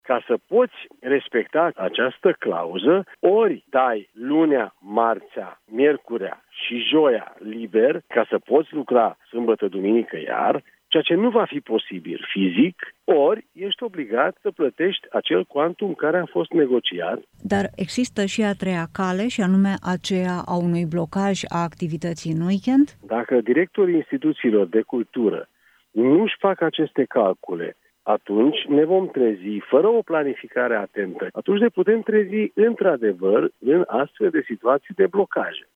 Ministrul Culturii, Demeter Andráș , la Europa FM.